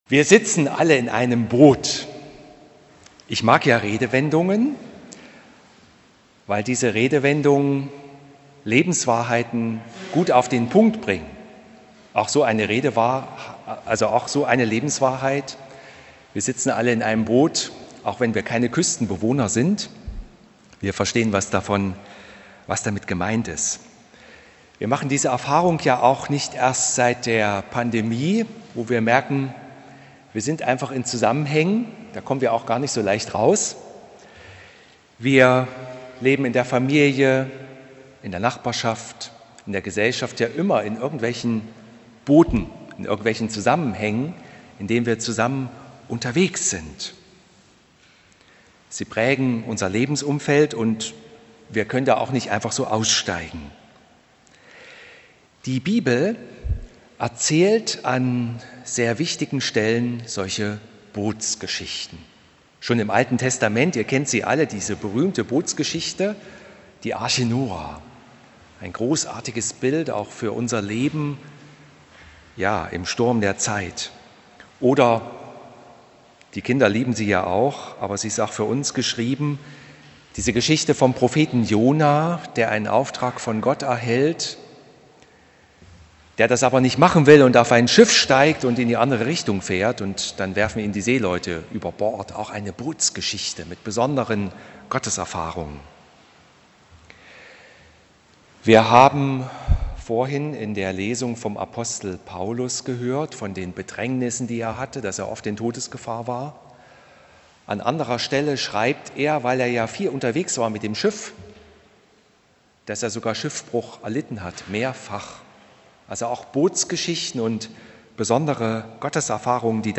Hören Sie hier die Predigt zu Matthäus 14,22-33